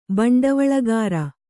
♪ baṇḍavaḷagāra